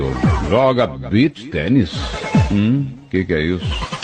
Joga Beach Tennis meme soundboard clip with energetic, fun vibe perfect for sporty or humorous moments.